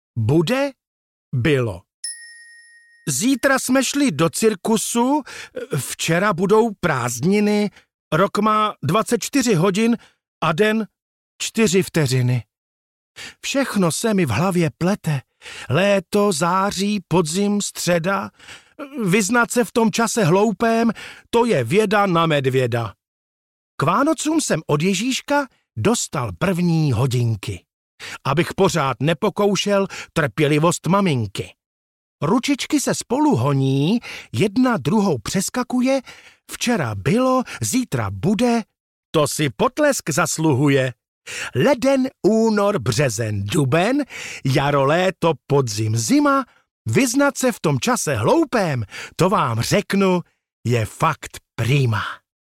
Básničky Vavule pro dětské kebule audiokniha
Ukázka z knihy